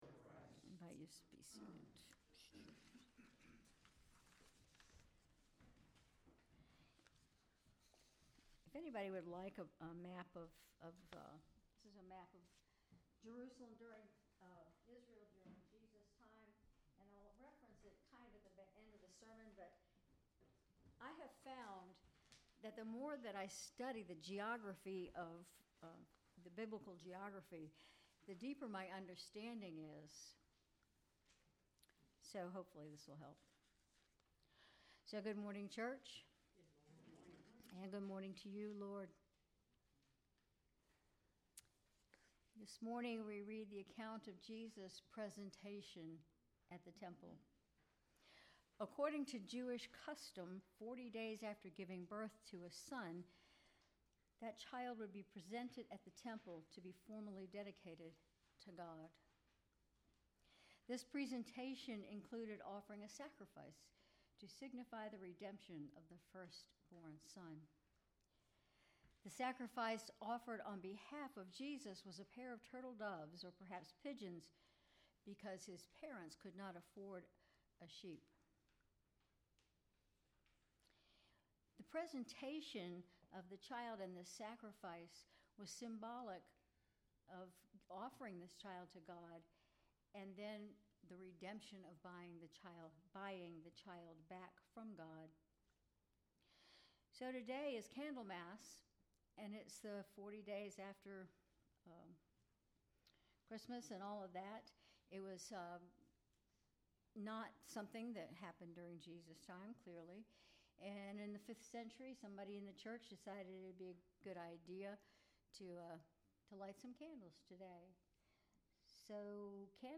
Sermon February 2, 2025
Sermon_February_2_2025.mp3